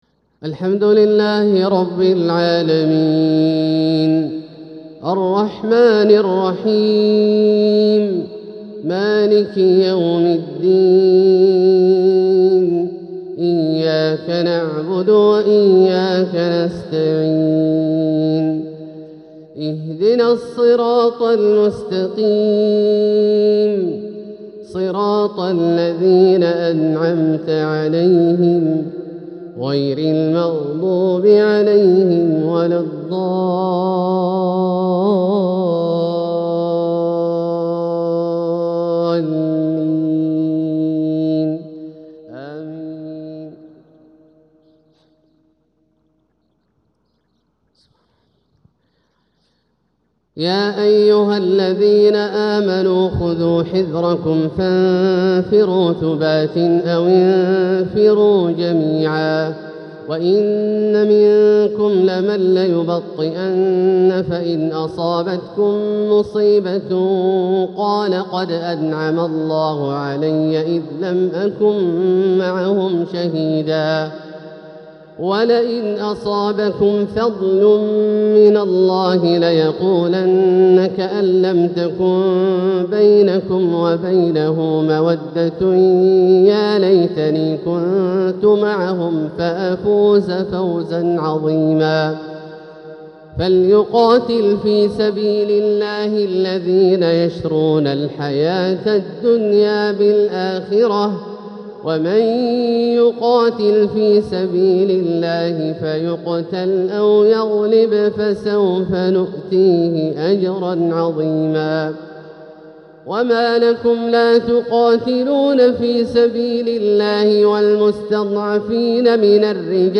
ترنم رائق للشيخ عبدالله الجهني من سورة النساء | فجر الاثنين 3 صفر 1447هـ > ١٤٤٧هـ > الفروض - تلاوات عبدالله الجهني